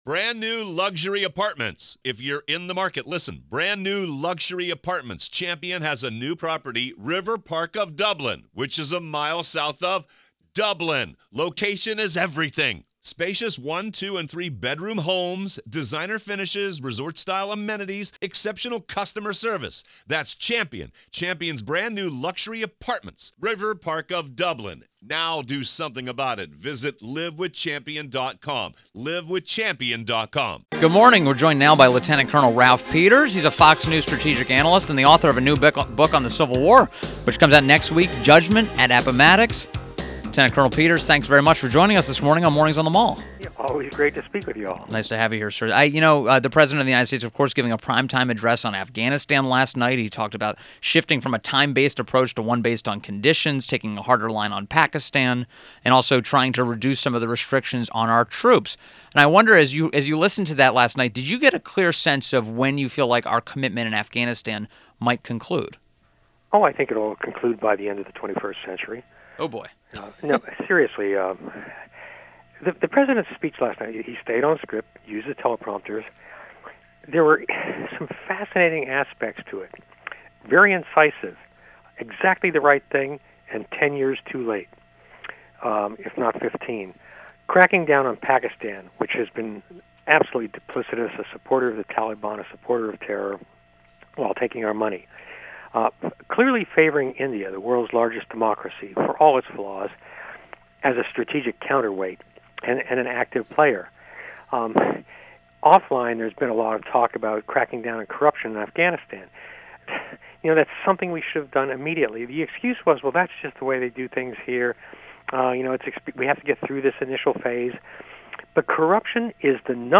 WMAL Interview - RALPH PETERS - 08.22.17